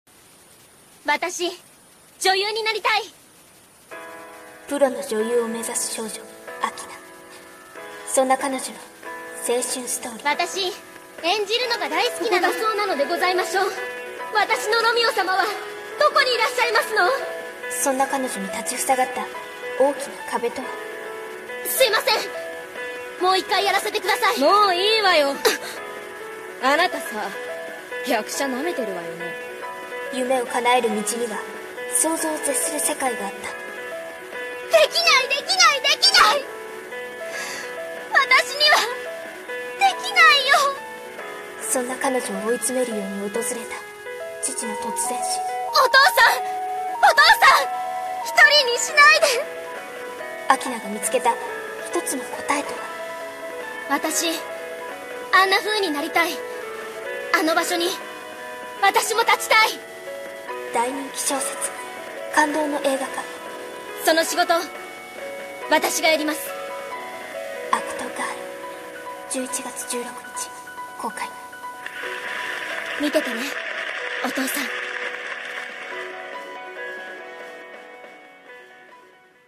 (声劇)